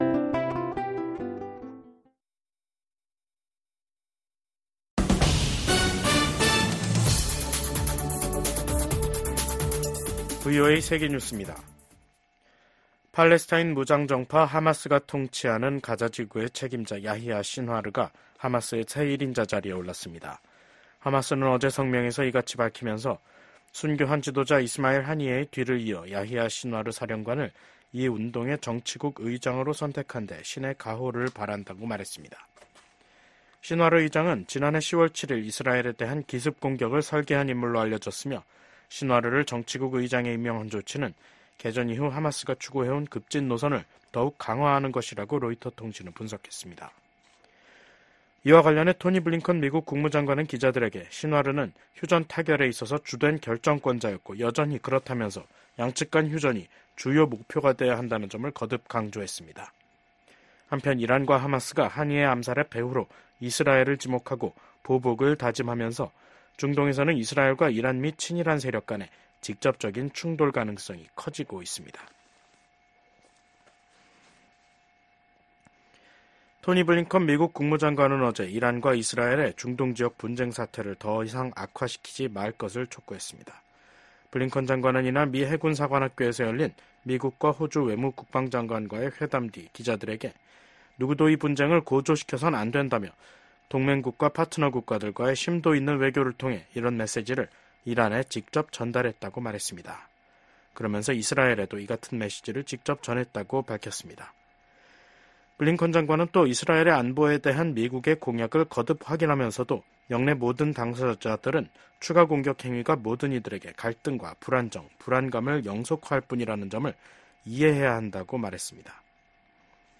VOA 한국어 간판 뉴스 프로그램 '뉴스 투데이', 2024년 8월 7일 2부 방송입니다. 오는 11월 미국 대선에서 민주당 후보로 나설 예정인 카멀라 해리스 부통령이 팀 월즈 미네소타 주지사를 부통령 후보로 지명했습니다. 미국과 호주의 외교∙국방장관들이 북한과 러시아의 군사 협력을 규탄했습니다.